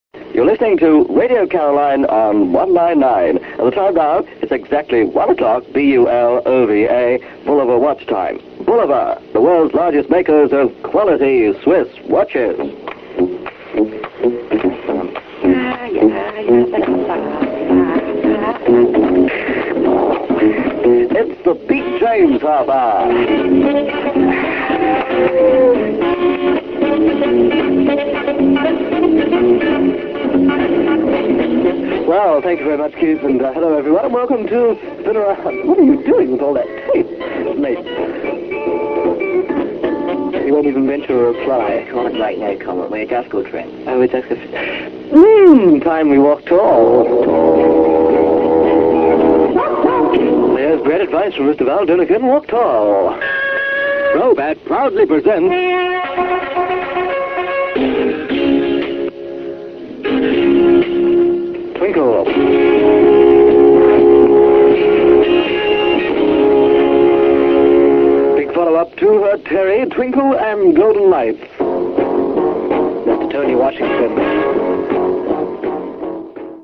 The voice at the start is Keith Skues.